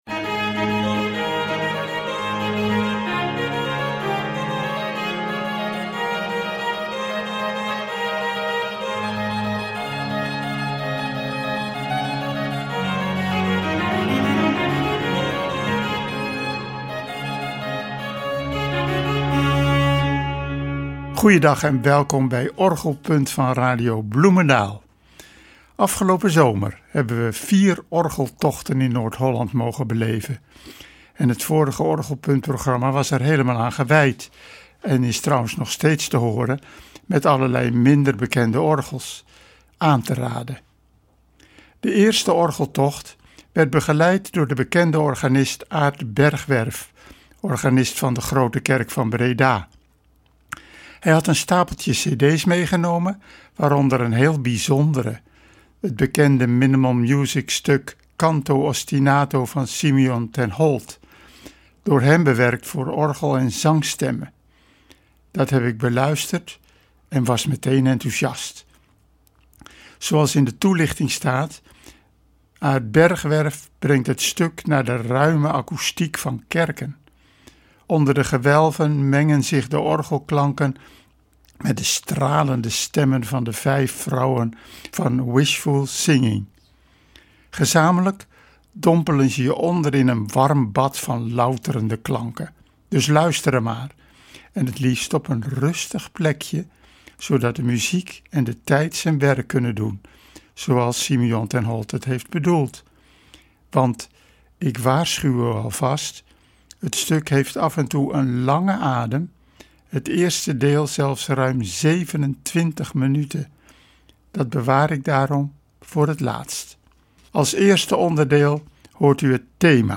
bewerkt voor orgel.
minimal music
bewerkt voor orgel en zangstemmen.